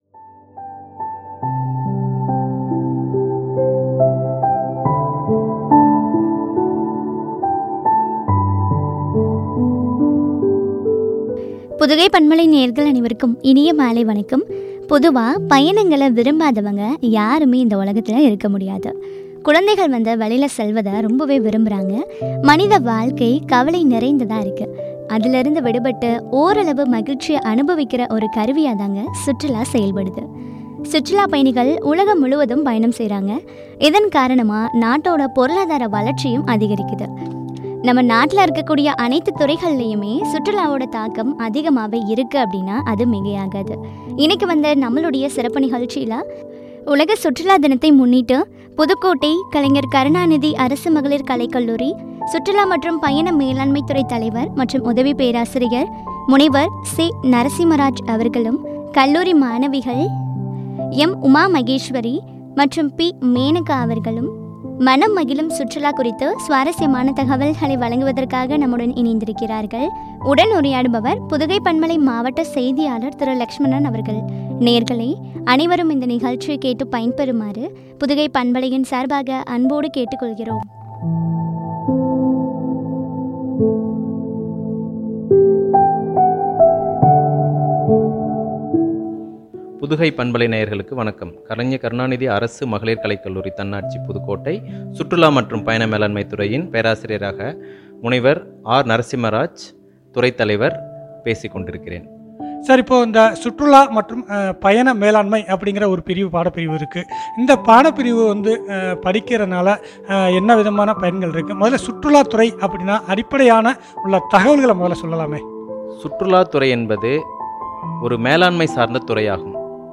“மனம் மகிழும் சுற்றுலா” குறித்து வழங்கிய உரையாடல்.